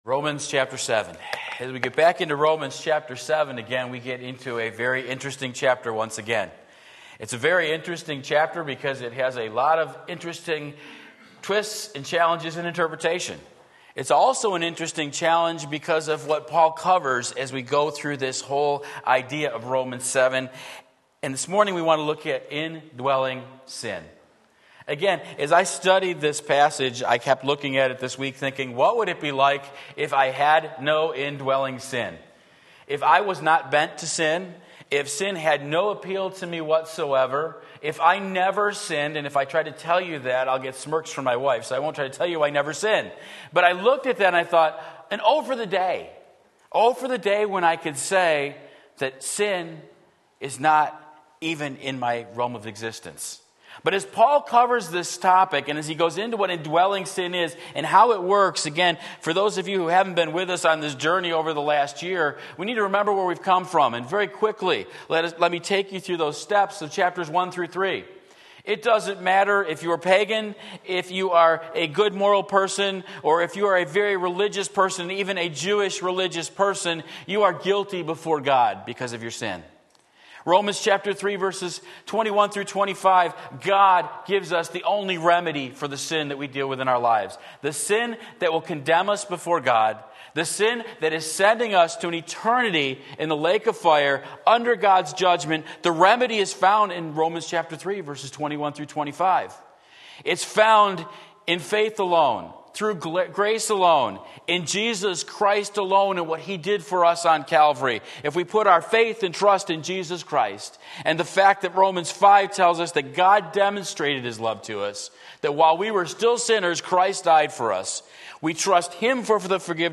Sermon Link
Indwelling Sin Romans 7:14-21 Sunday Morning Service